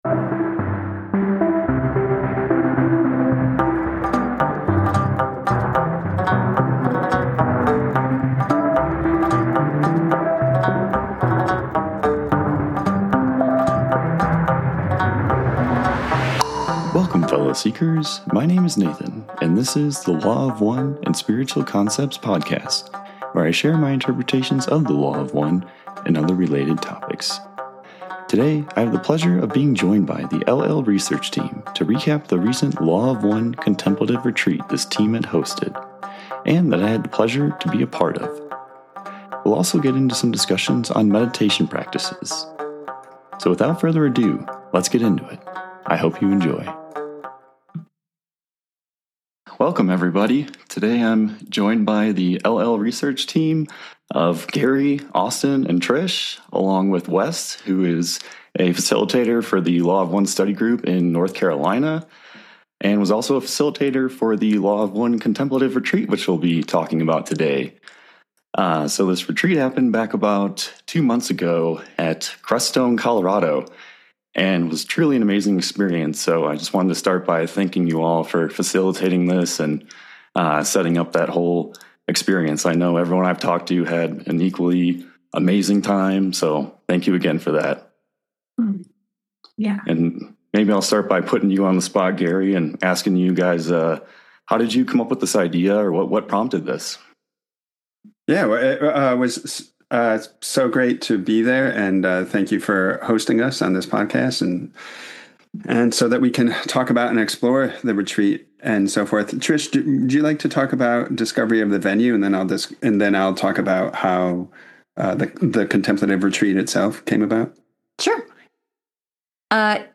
l-l-research-discussion-the-law-of-one-contemplative-retreat-episode-27.mp3